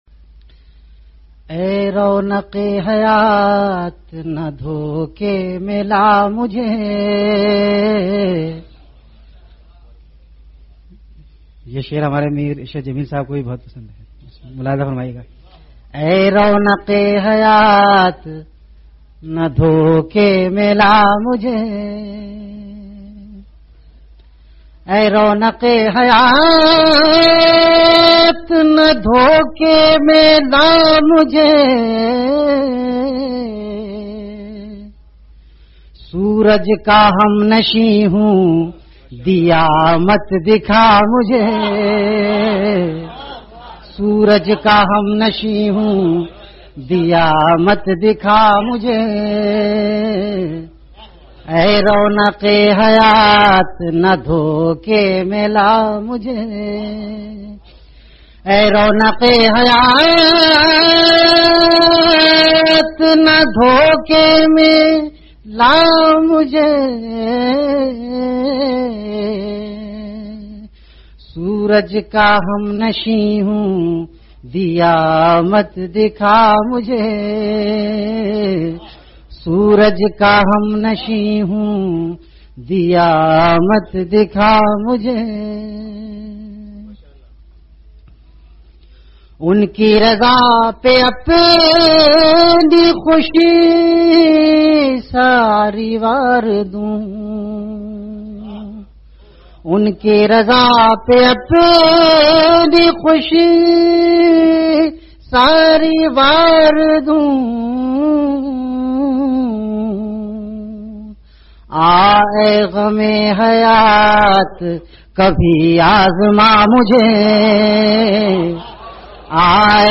CategoryAshaar
VenueKhanqah Imdadia Ashrafia
Event / TimeAfter Isha Prayer